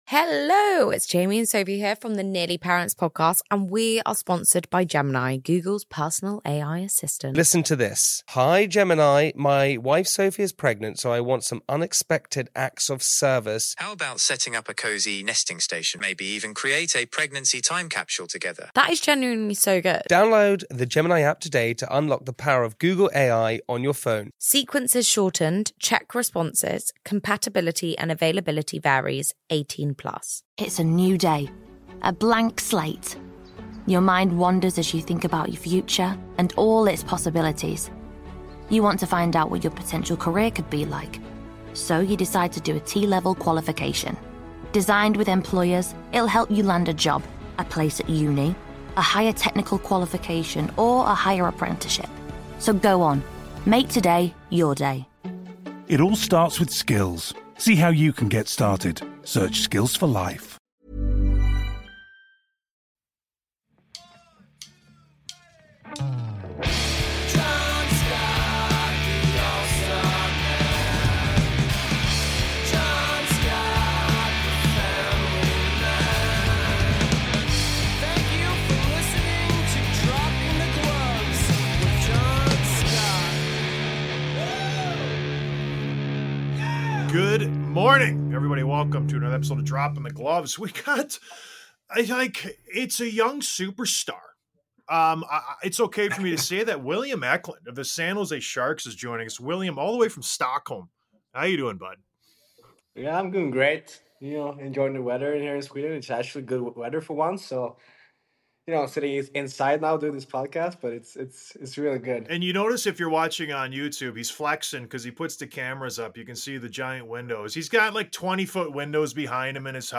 Interview w/ San Jose Shark star William Eklund